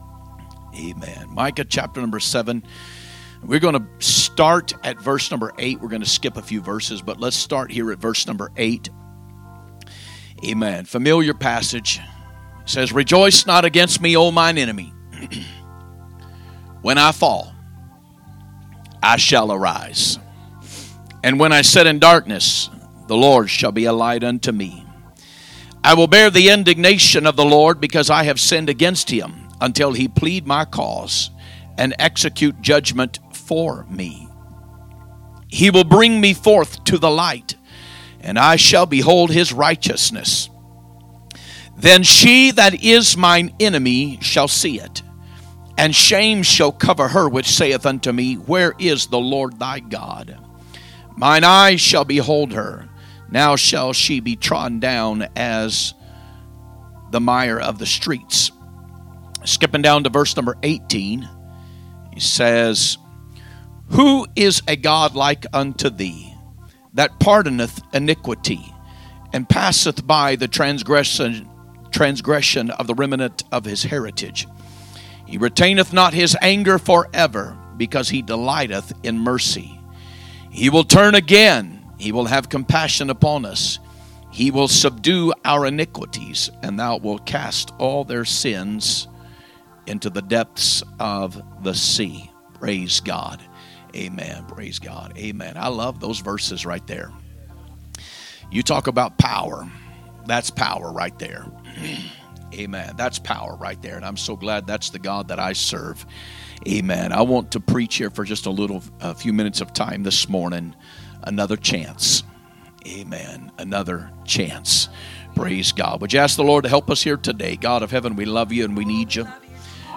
Sunday Morning Message